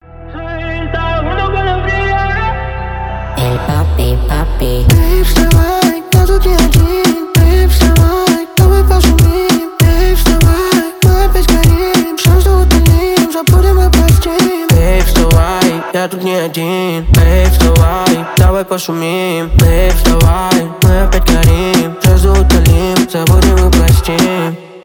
танцевальные
рэп
ритмичные